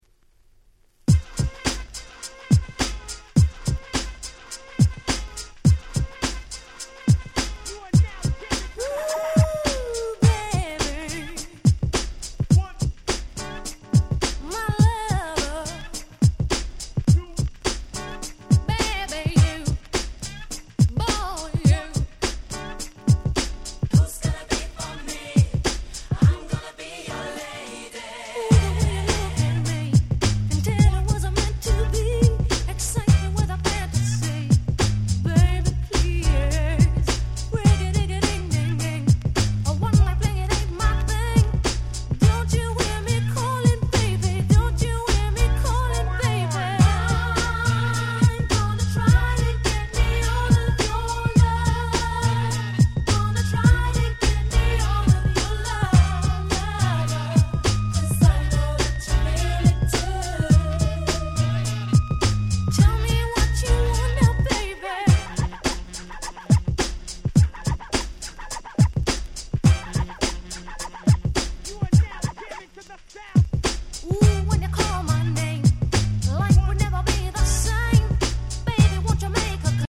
94' Nice UK R&B !!